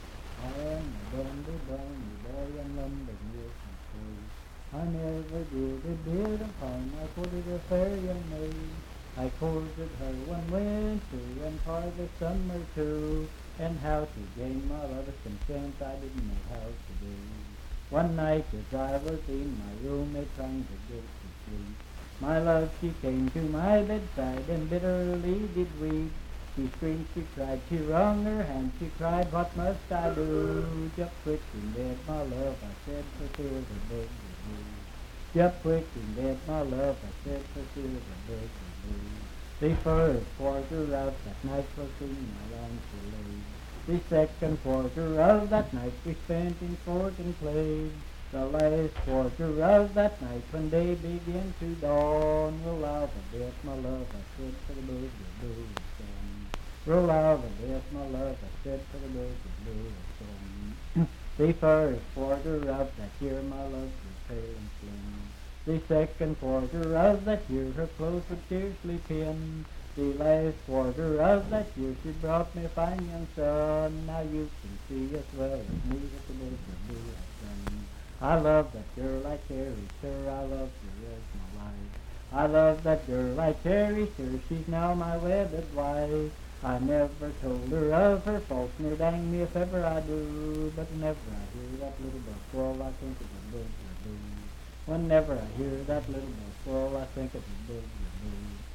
Bugaboo - West Virginia Folk Music | WVU Libraries
Unaccompanied vocal music
in Riverton, W.V.
Bawdy Songs, Love and Lovers
Voice (sung)